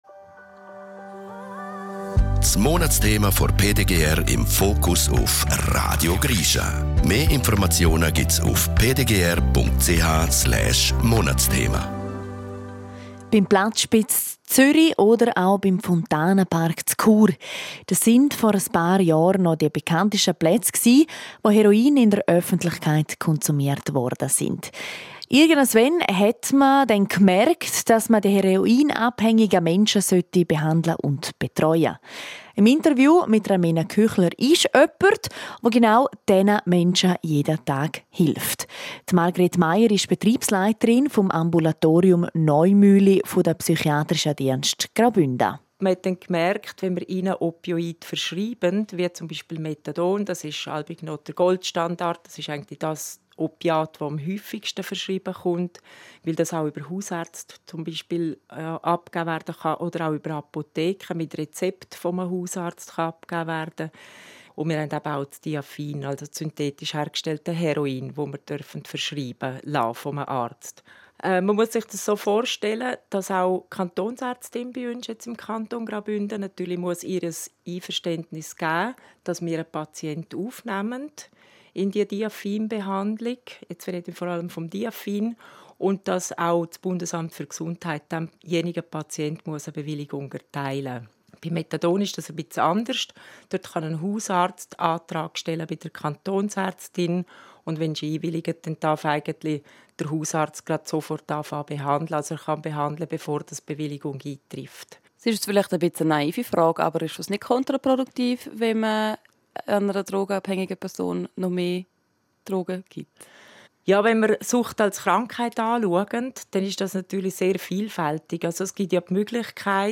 Radiobeiträge